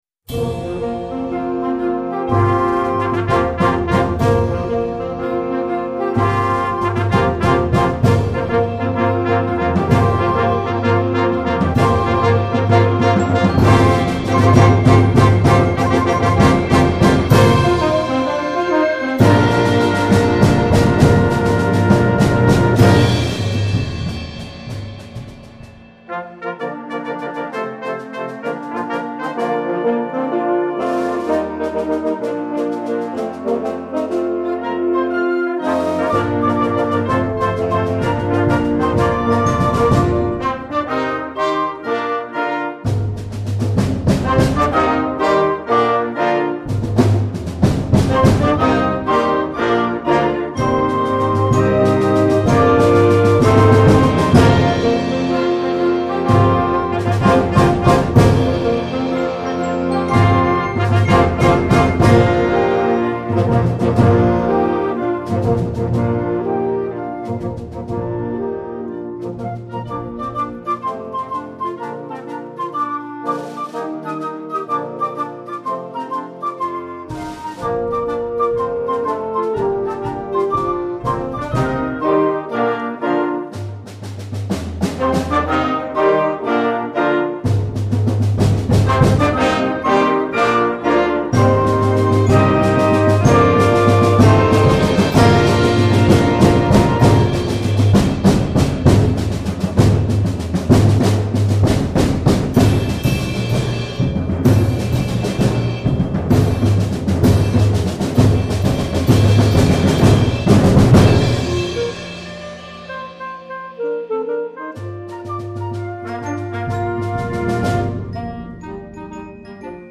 [Concert Band]